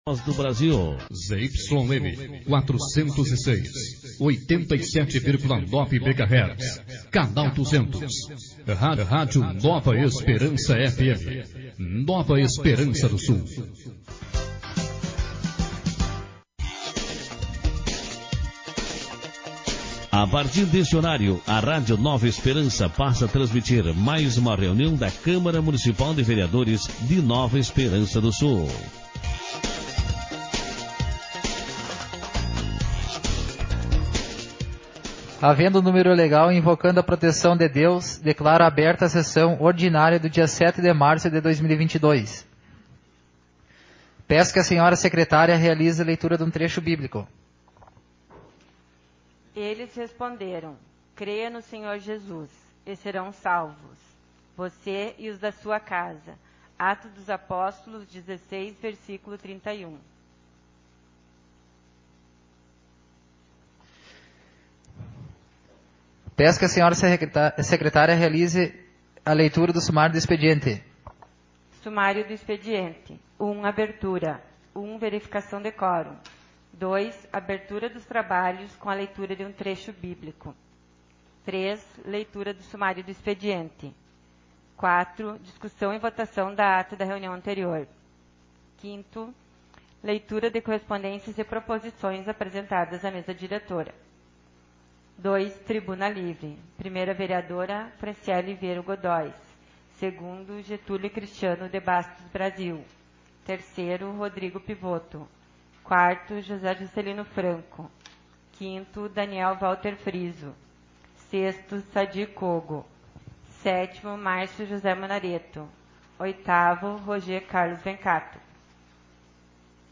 audios sessão
Sessão Ordinária 05/2022